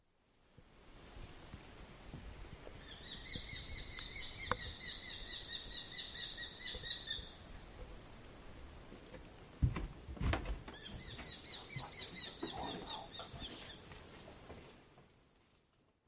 Tuulihaukka ääntelee nopein tikutuksin.
nuolihaukka.mp3